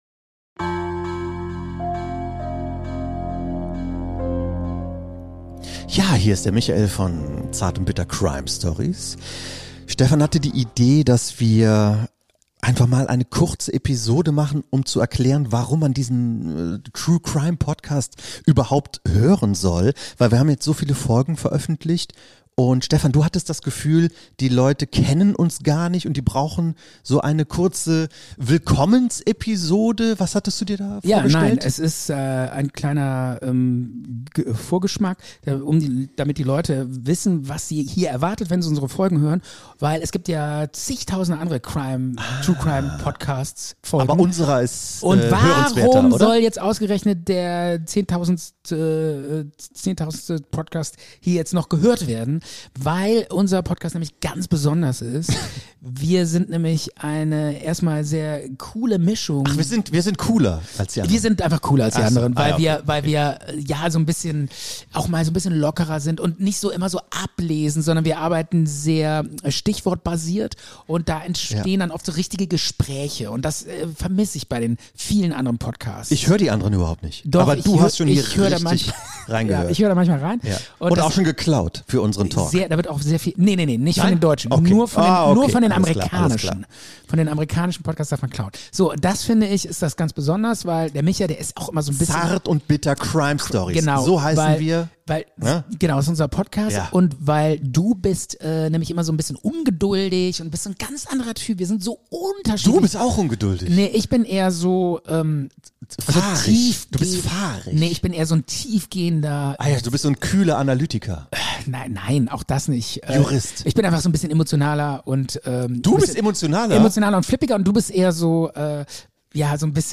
Ein seltsamer True Crime Podcast.
Manchmal mit Gästen.